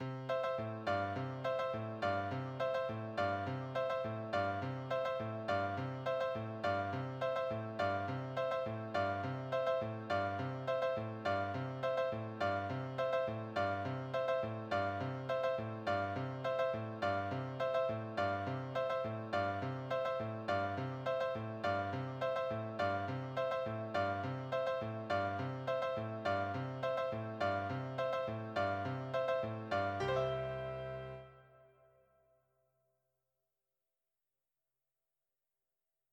1.4 (6) - Accompaniment.mp3